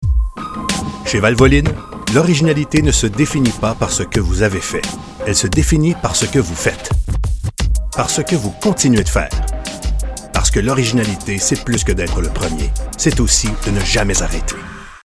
warm, authoritative French Canadian voice over with bass resonance
Automotive
All this in a well-soundproofed cedar wardrobe that smells good!
Bass